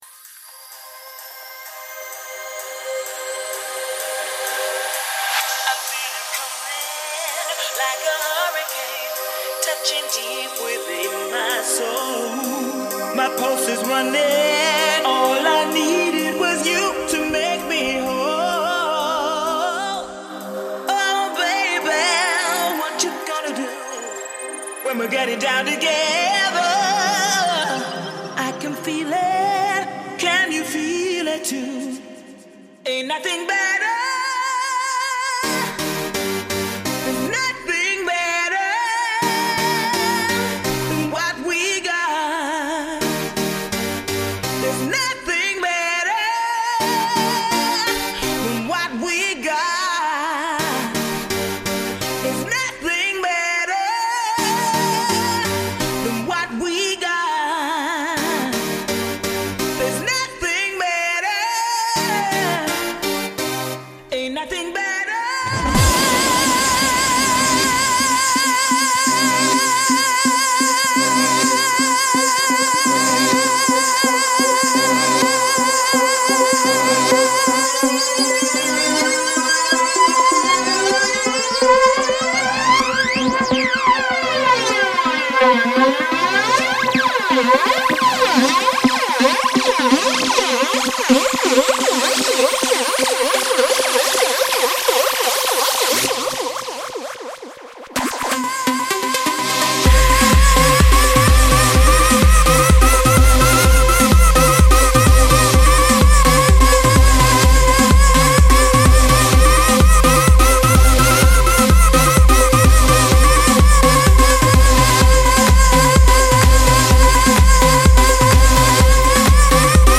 House